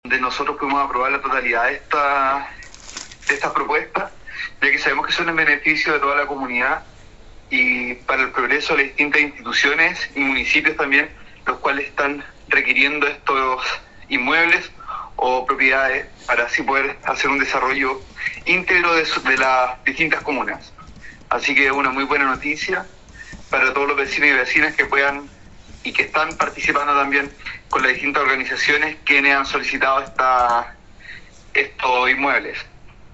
Consejero-Enrique-Larre.mp3